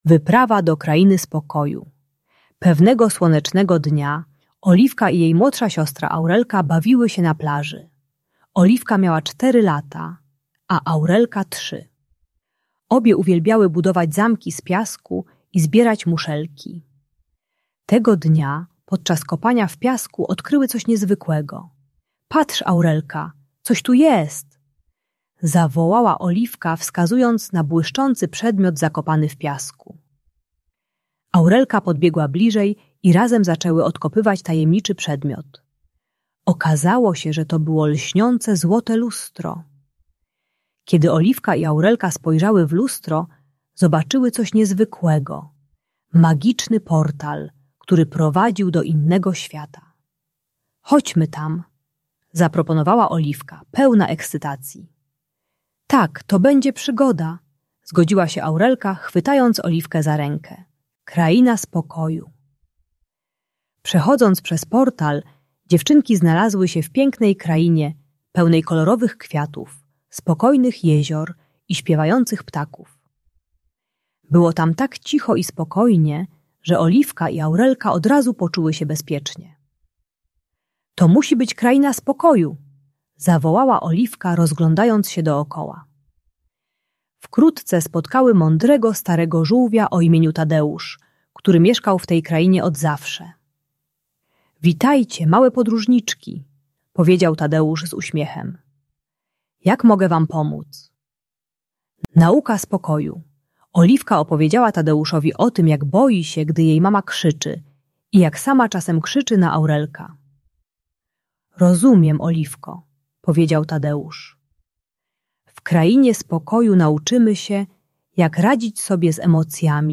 Wyprawa do Krainy Spokoju - Lęk wycofanie | Audiobajka